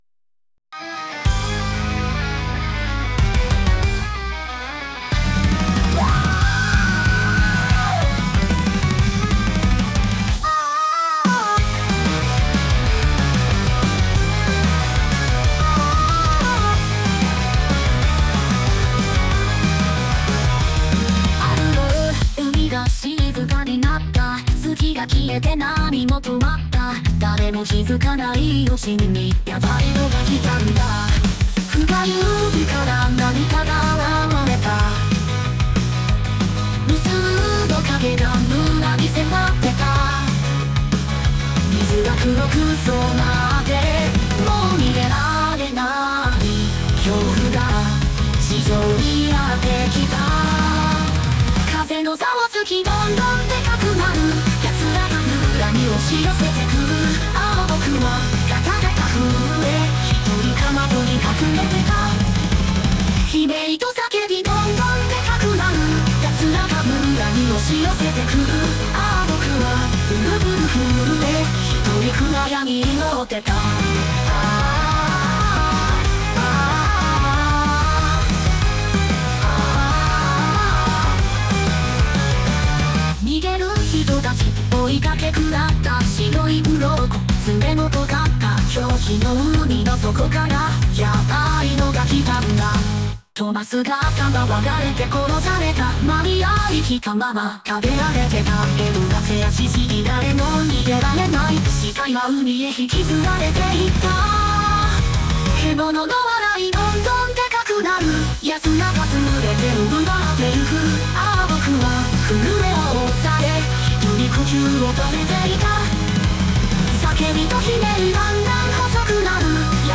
今更ながら動画版の音がシャリシャリしている気がするのでこちらは加工工程の中のシャリシャリしてないバージョンです。